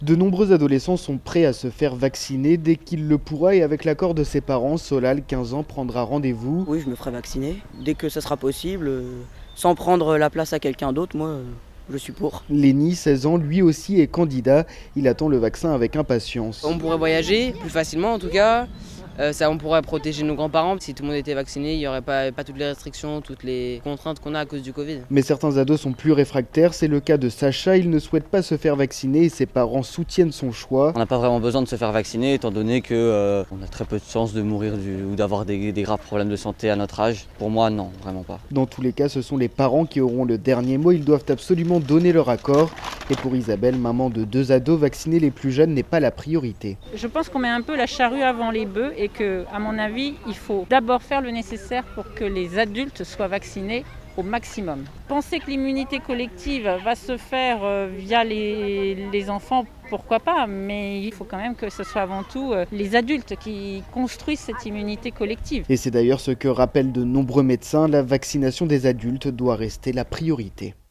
Le chef de l’État a annoncé l’ouverture, dès le 15 juin 2021, de la vaccination pour les adolescents de 12 à 18 ans avec le vaccin Pfizer, le seul à avoir obtenu l’aval des autorités pour cette tranche d’âge. Reportage